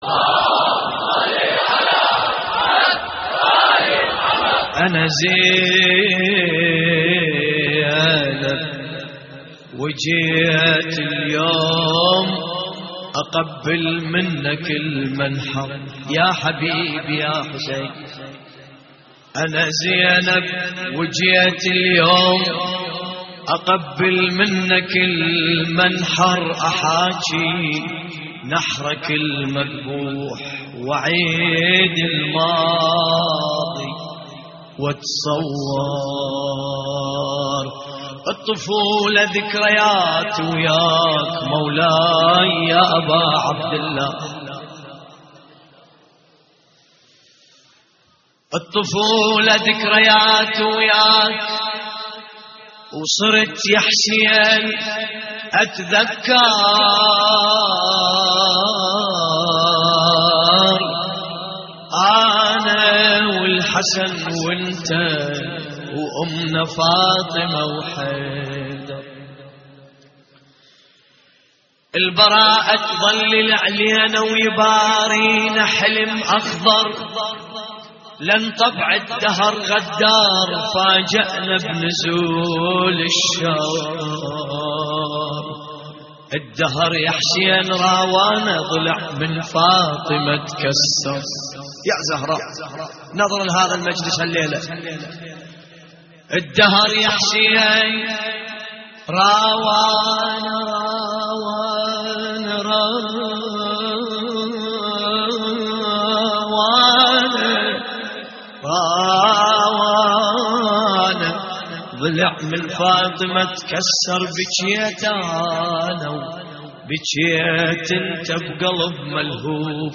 تحميل : انا زينب وجيت اليوم اقبل منك المنحر / الرادود باسم الكربلائي / اللطميات الحسينية / موقع يا حسين
موقع يا حسين : اللطميات الحسينية انا زينب وجيت اليوم اقبل منك المنحر - نعي لحفظ الملف في مجلد خاص اضغط بالزر الأيمن هنا ثم اختر (حفظ الهدف باسم - Save Target As) واختر المكان المناسب